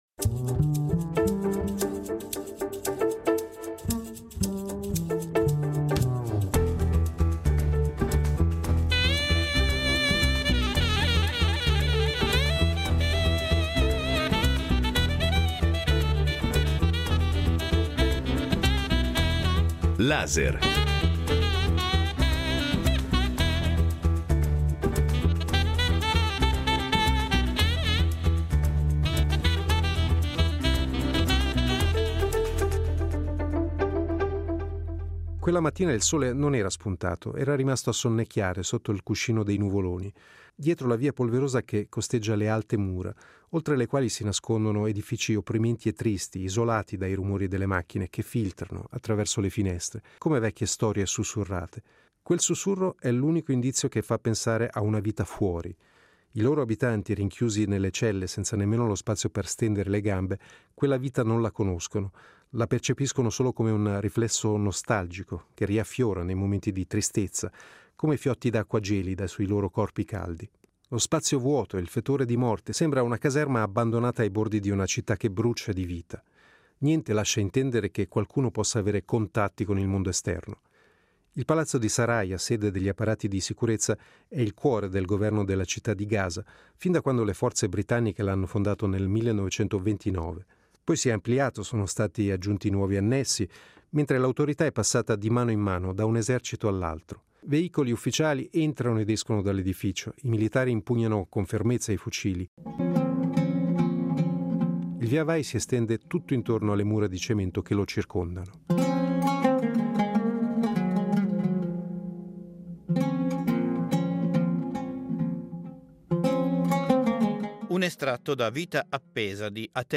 Incontro con lo scrittore palestinese Atef Abu SAif